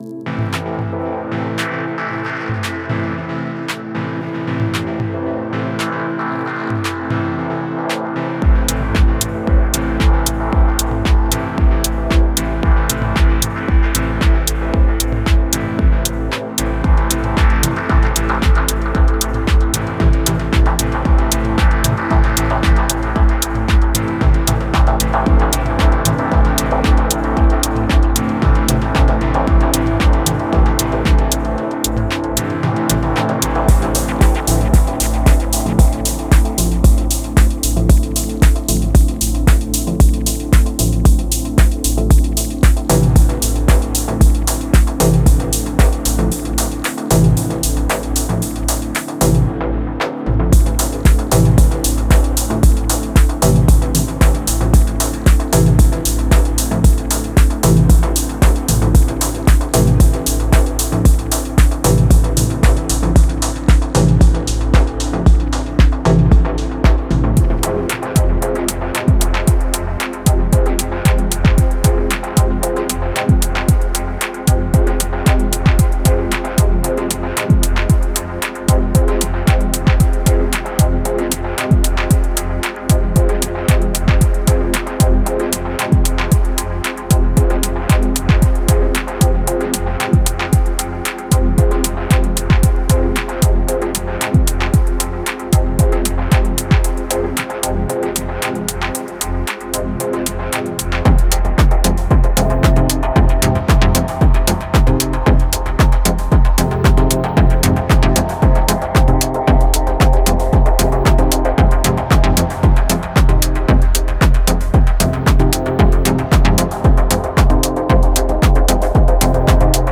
Genre:Dub Techno
催眠的なグルーヴ、豊かなアンビエントテクスチャ、力強いベースラインを制作する際、必要なすべてのツールを提供します。
デモサウンドはコチラ↓
10 Full Drum Loops 114 Bpm